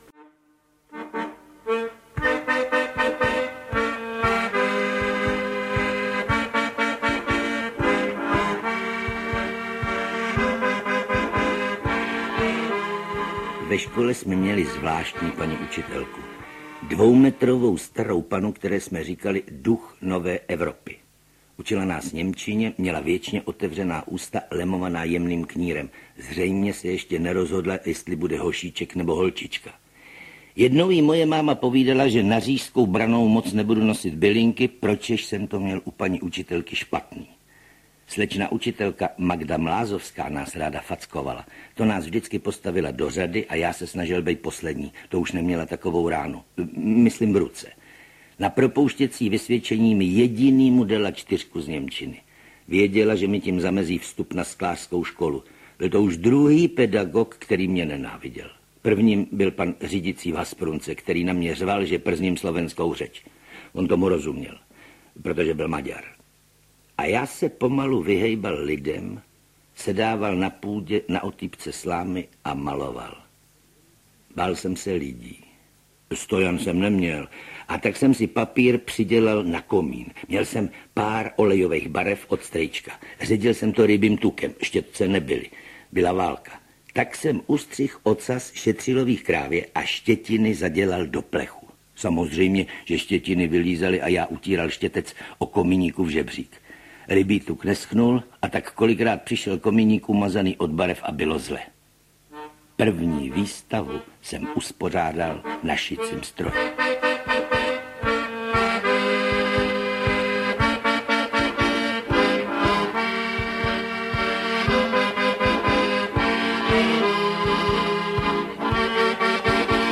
Knihu vzpomínek svého dlouholetého přítele malíře, grafika, ilustrátora a skvělého vypravěče Vladimíra Komárka, plnou jeho jedinečného humoru, osobitě tlumočí Josef Vinklář. Autentické historky živě vypráví sám mistr.
Ukázka z knihy
Literární dílo je prokládáno historkami, které vypráví sám malíř a uslyšíte mimo jiné i autentický záznam jeho hlasu z budovy Národního divadla z období listopadových dnů roku 1989, který má neopakovatelnou atmosféru.
• InterpretJosef Vinklář, Vladimír Komárek